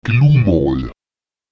Grundsätzlich wird in yorlakesischen Wörtern die erste Silbe betont: